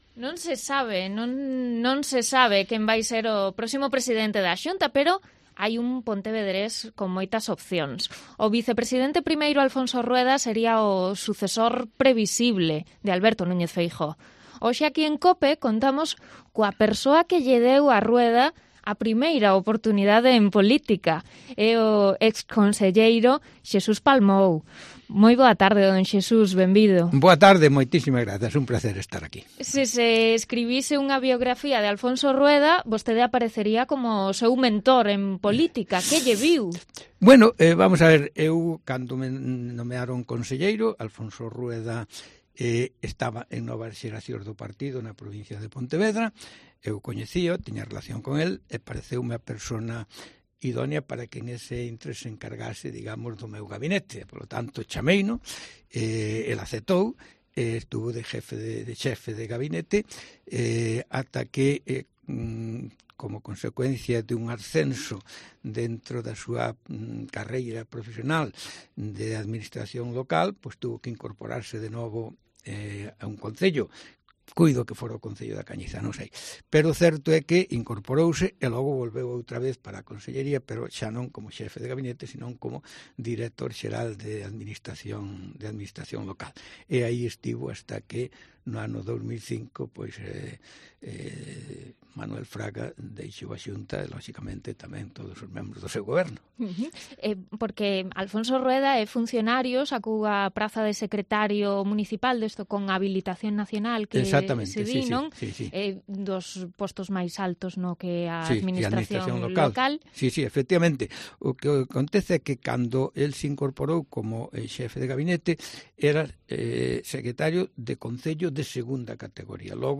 Entrevista co exconselleiro Xesús Palmou sobre a súa aposta por Alfonso Rueda